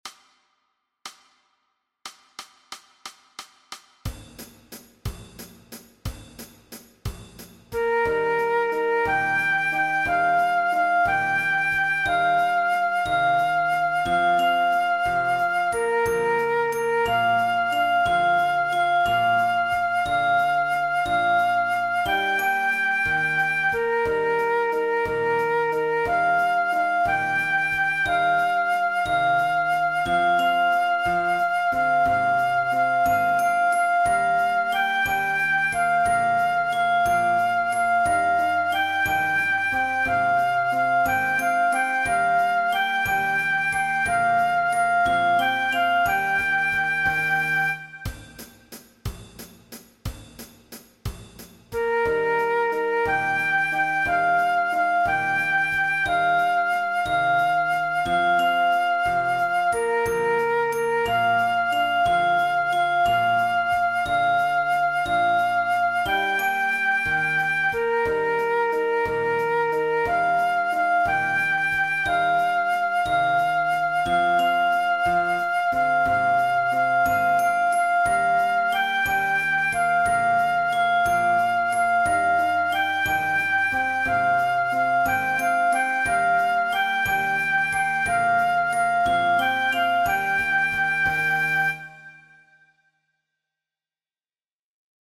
Aangepaste oefening NT 6.4 Dwarsfluit
Dwarsfluit aangepast